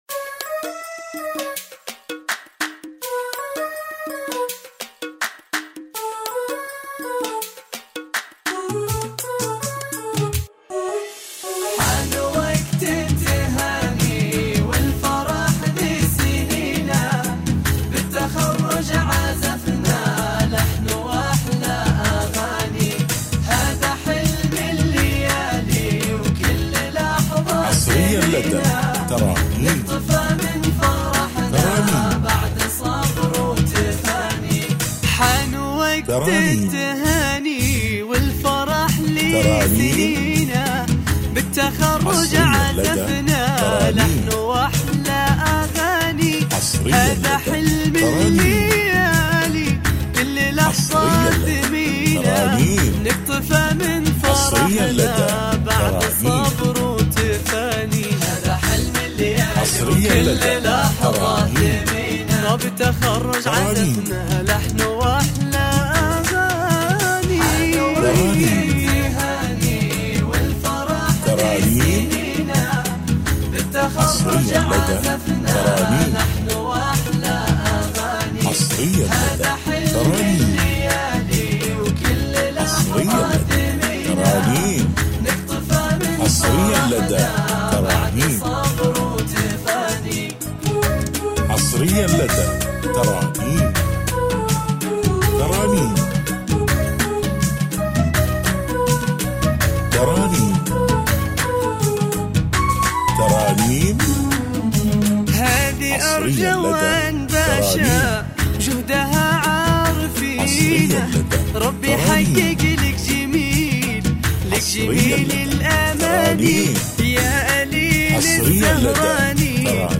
زفات موسيقى